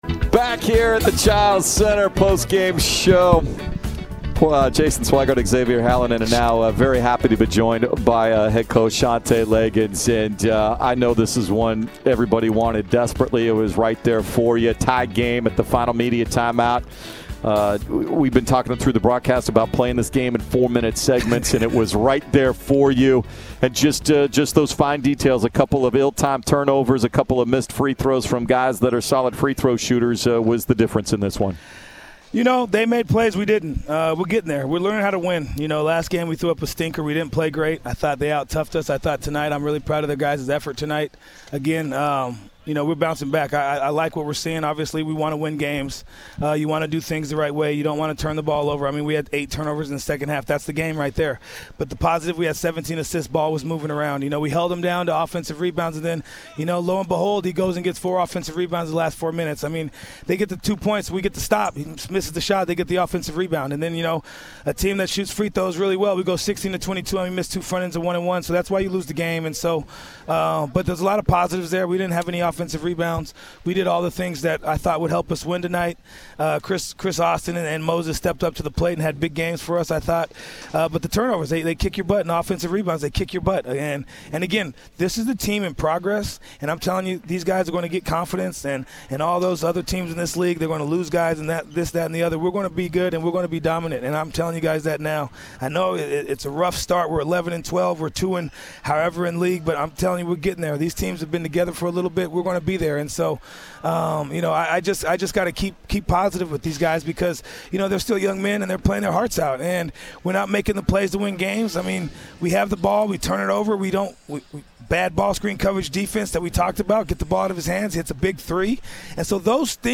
Men's Basketball Radio Interviews